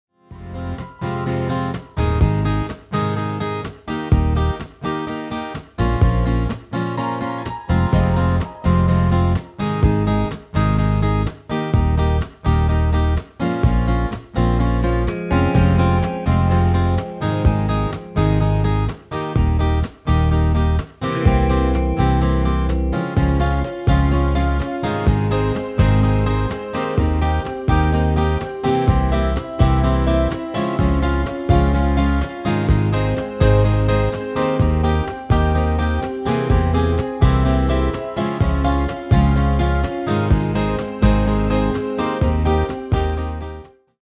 Demo/Koop midifile
Genre: Pop & Rock Internationaal
Toonsoort: F
- Vocal harmony tracks
Demo's zijn eigen opnames van onze digitale arrangementen.